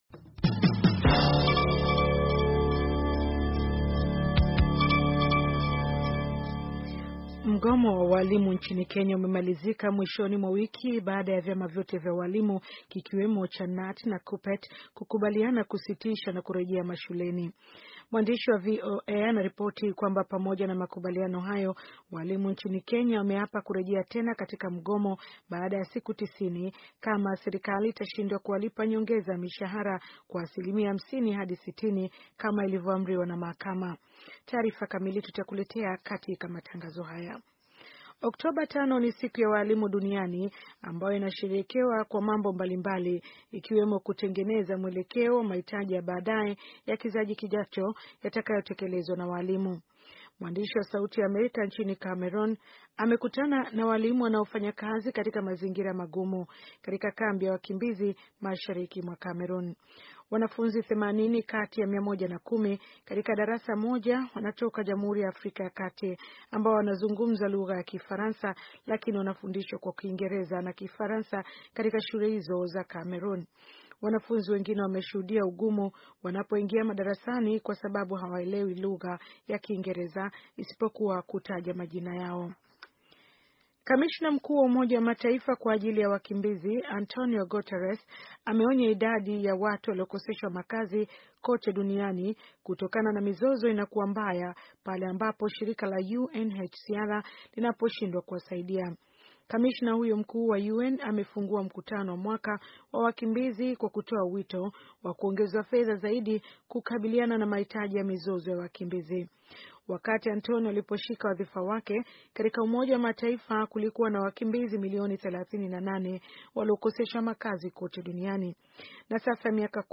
Taarifa ya habari - 4:23